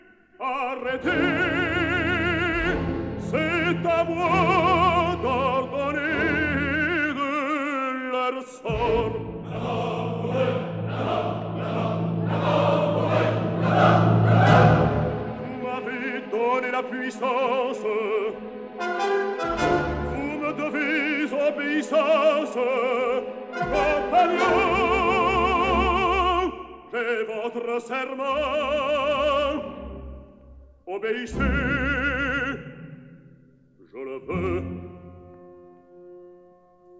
soprano
tenor
baritone
bass